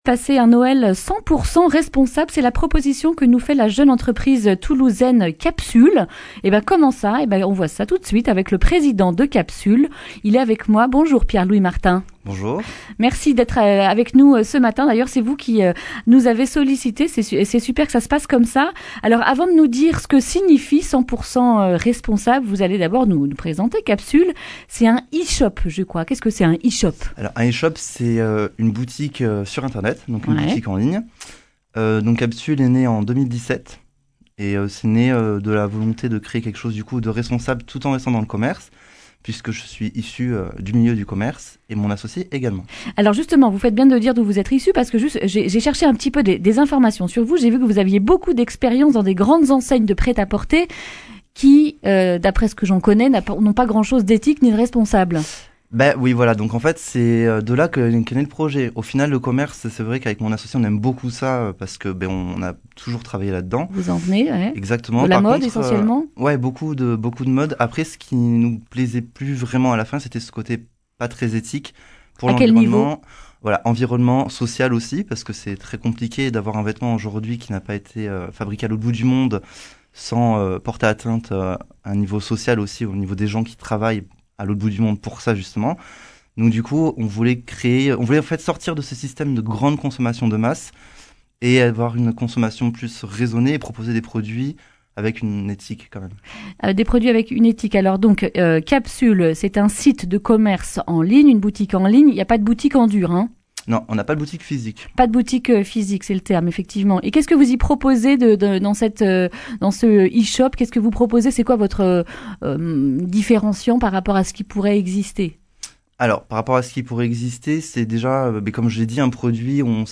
jeudi 19 décembre 2019 Le grand entretien Durée 10 min